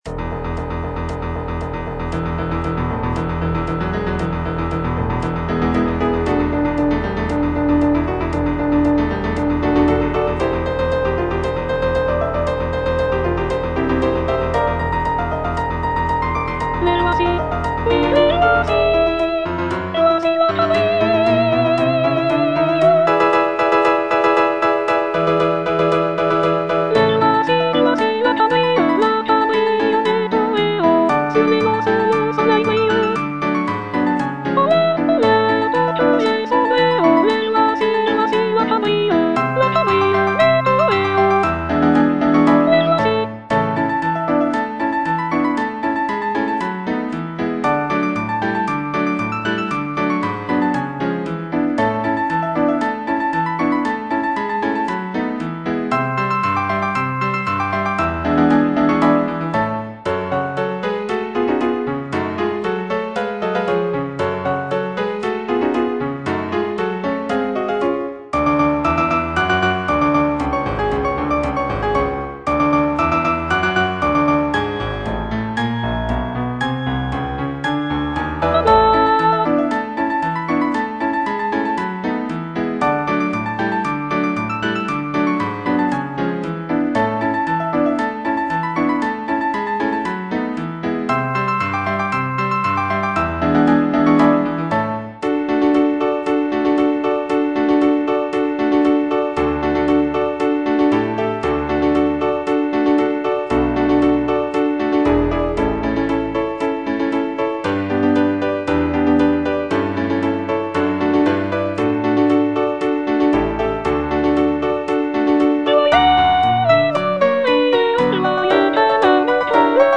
G. BIZET - CHOIRS FROM "CARMEN" Les voici (soprano III) (Voice with metronome) Ads stop: auto-stop